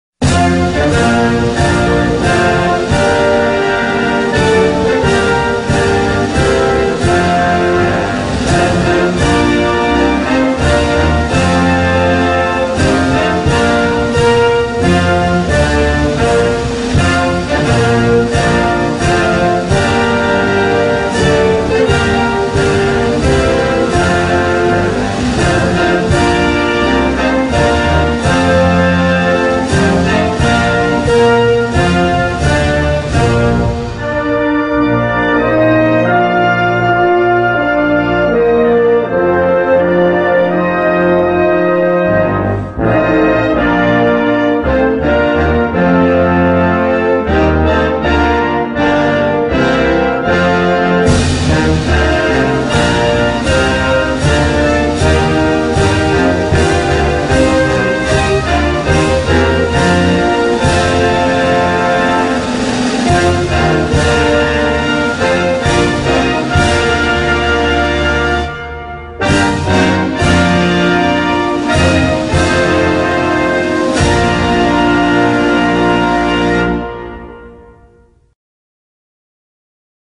Category: Sports   Right: Commercial